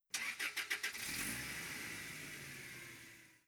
carEngineStart.wav